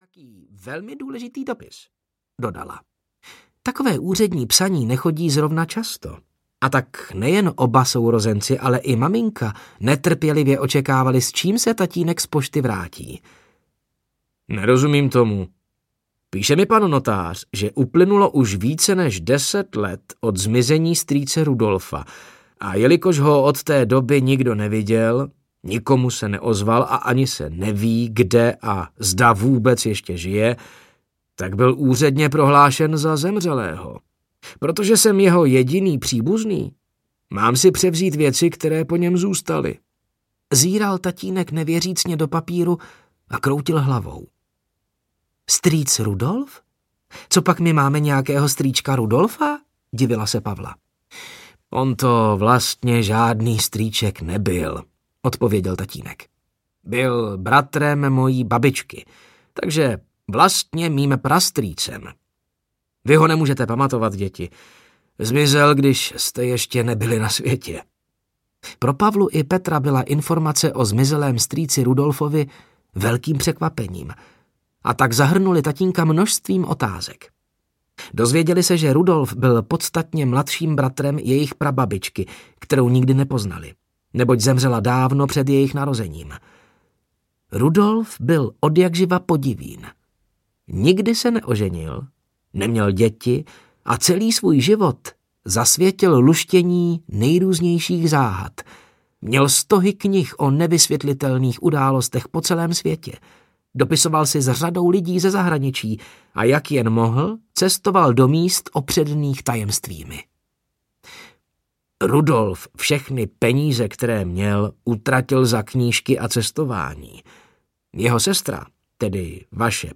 Záhadné případy pro děti audiokniha
Ukázka z knihy